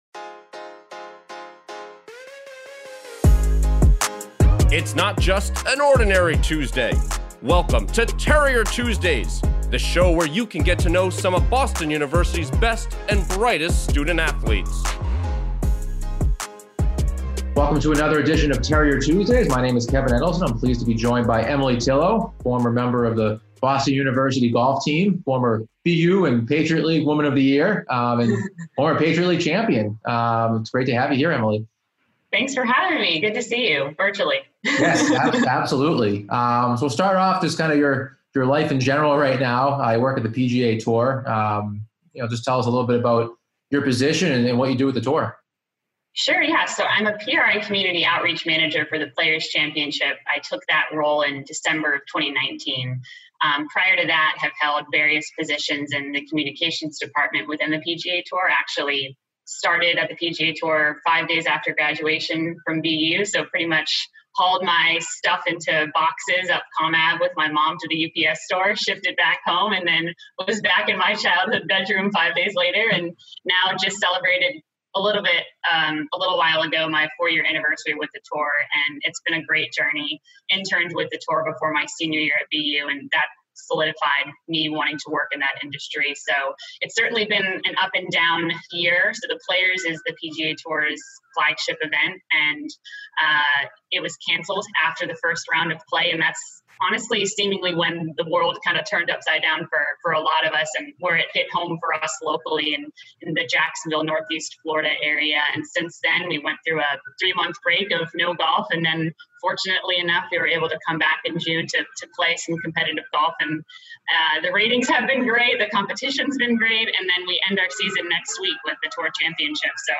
Each week we'll feature a new interview with one of our former student-athletes.